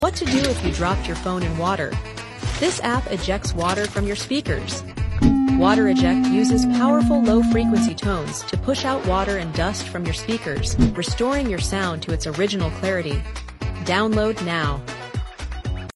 Fix Broken Noise from your sound effects free download